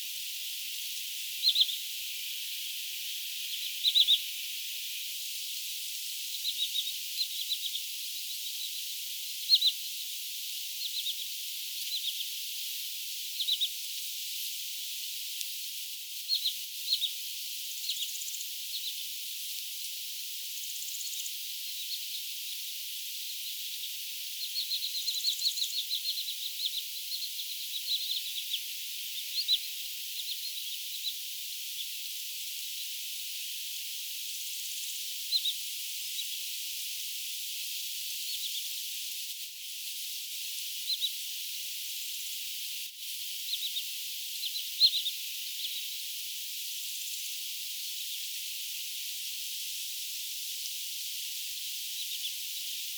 lirojen ääntelyä rannalla
lirojen_aantelya_rannalla.mp3